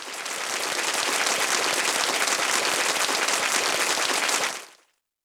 Roland.Juno.D _ Limited Edition _ GM2 SFX Kit _ 00.wav